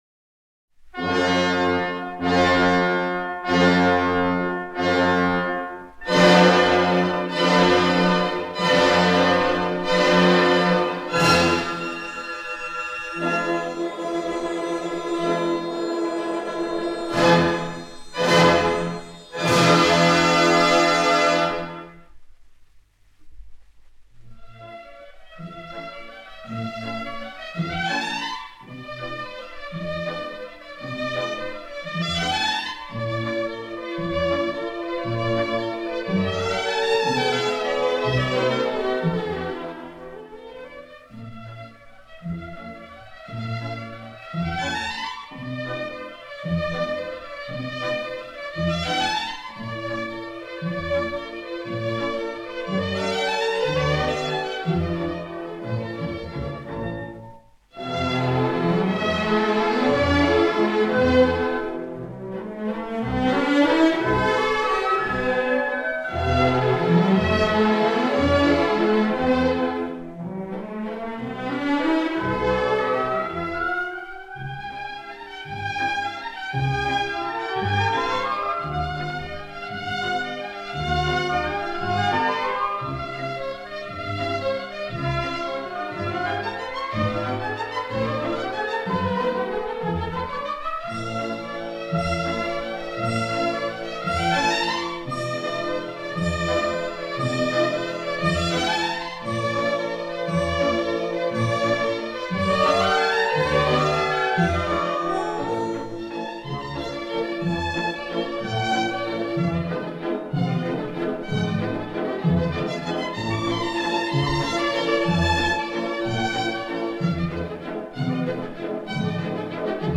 CHARLES GOUNOD. "Faust" ballet music. Berlin Philharmoniker
01-faust-ballet-music_-i.-les-nubiennes-allegretto_-mouvement-de-valse.m4a